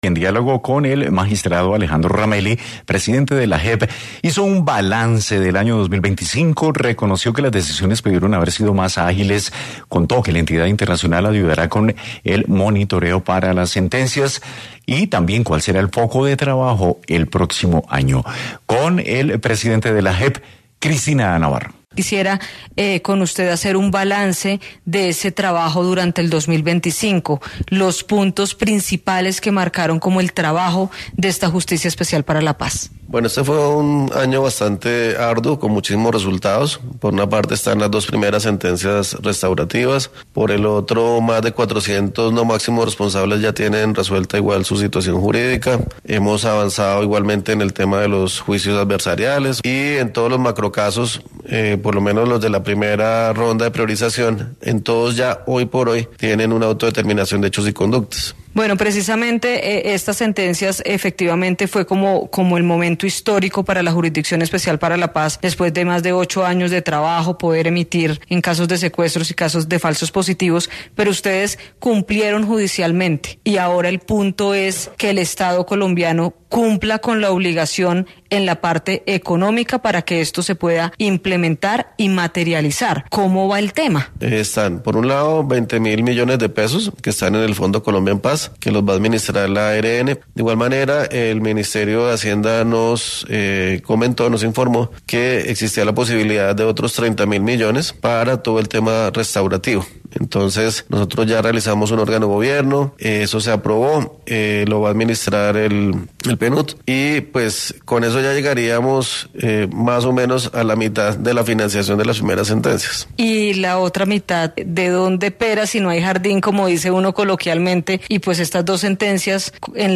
En diálogo con Caracol Radio el magistrado Alejandro Ramelli, presidente de la Jurisdicción Especial para la Paz, hizo un balance de este 2025 y destacó las primeras sentencias emitidas por esta justicia transicional.